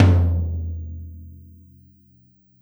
Tom Shard 01.wav